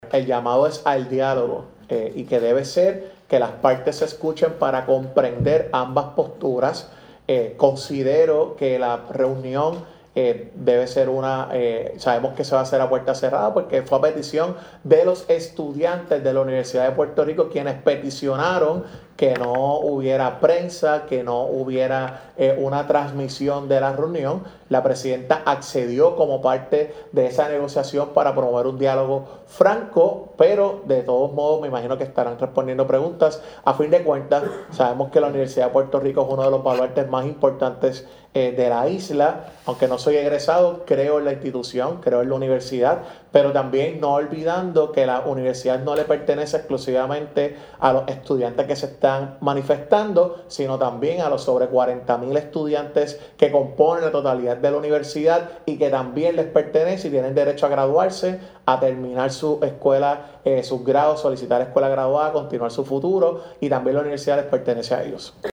“A fin de cuentas, sabemos que la Universidad de Puerto Rico es uno de los pilares más importantes de la isla.   Aunque no soy egresado, creo en la institución, creo en la universidad, pero también no olvidando que la universidad no le pertenece exclusivamente a los estudiantes que se están manifestando,   sino también a los sobre 40 mil estudiantes que componen la totalidad de la universidad   y que también les pertenece y tienen derecho a graduarse  a terminar sus grados la universidad les pertenece a ellos”, dijo el funcionario.